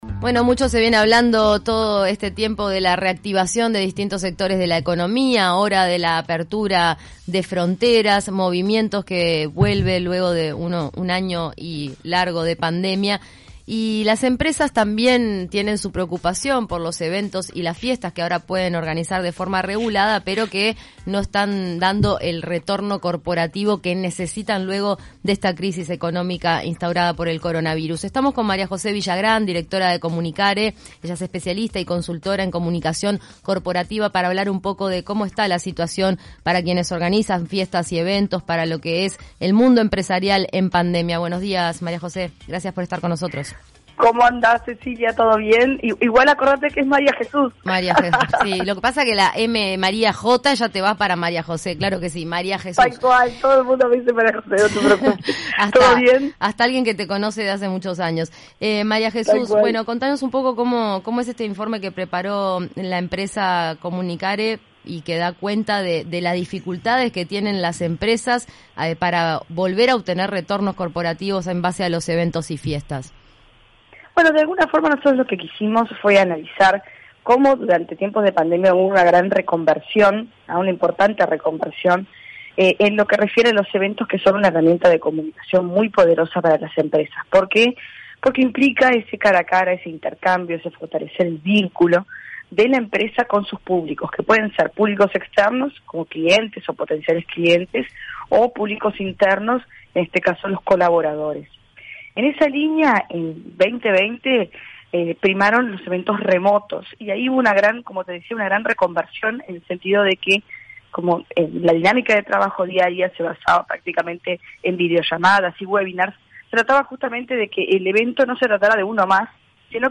En entrevista con 970 Noticias Primera Edición